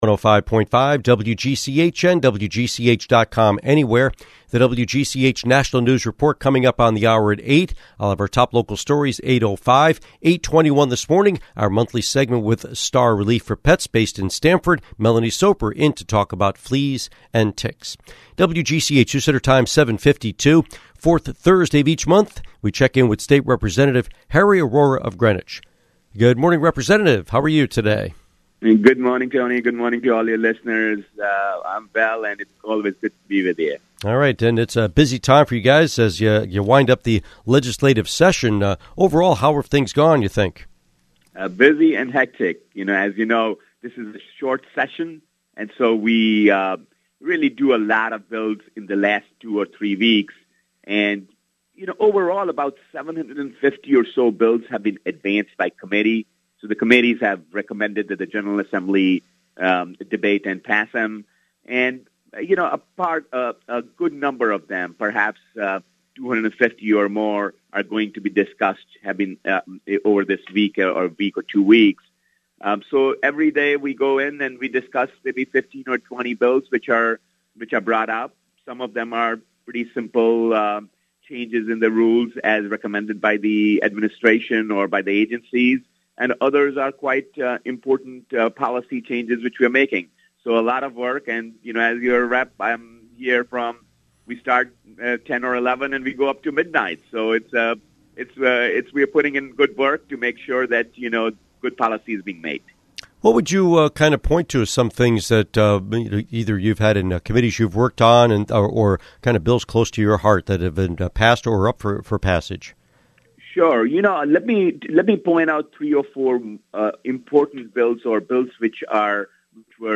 Interview with State Representative Arora